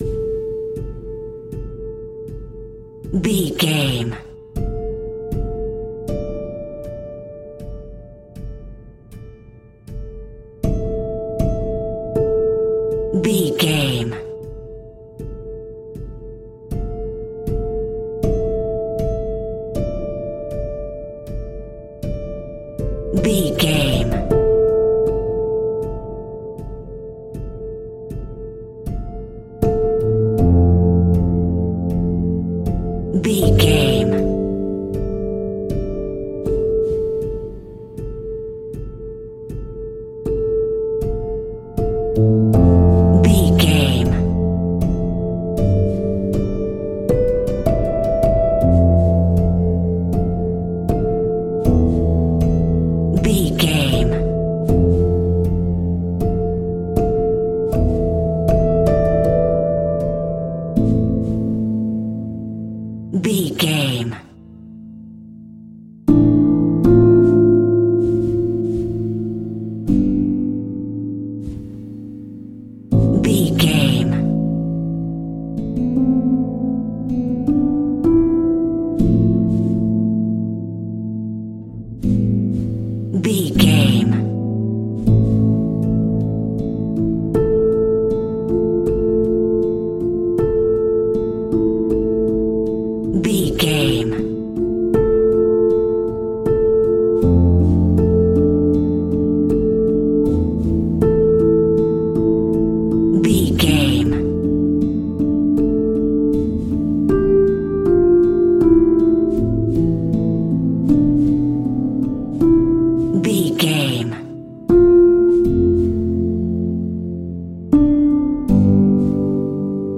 Aeolian/Minor
suspense
synthesiser